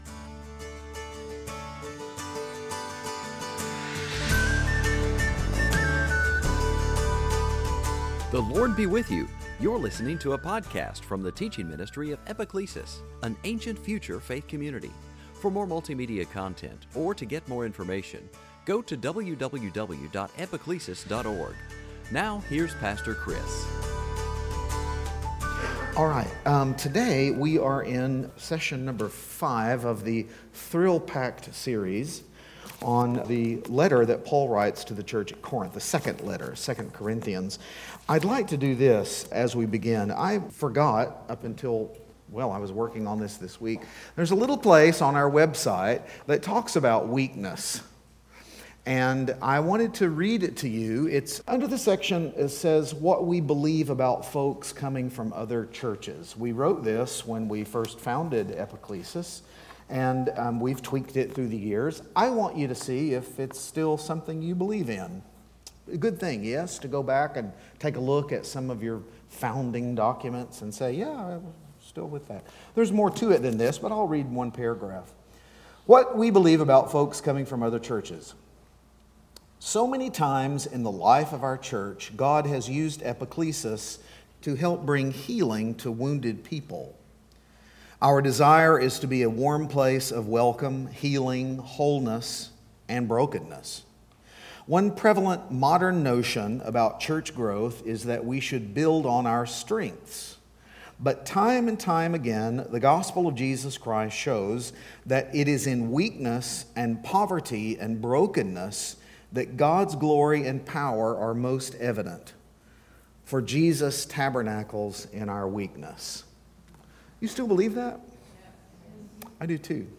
Series: Sunday Teaching